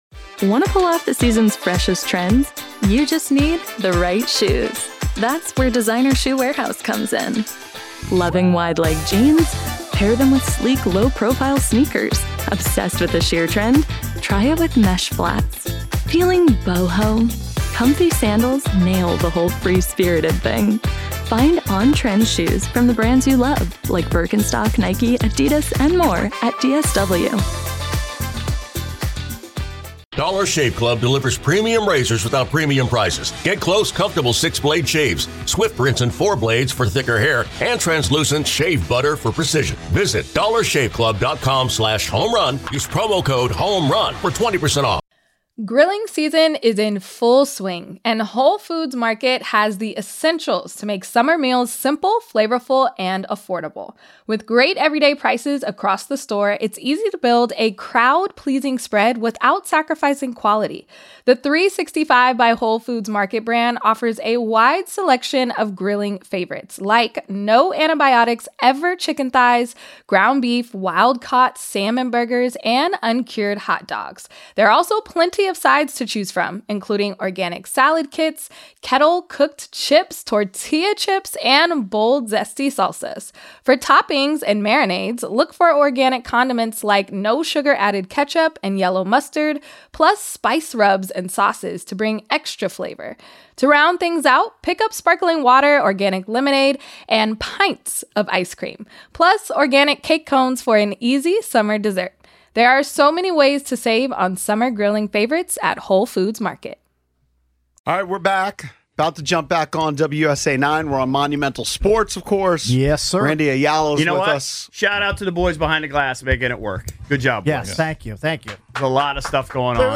From 01/10 Hour 1: The Sports Junkies simulcast on WUSA9 to preview the Commanders vs Bucs game.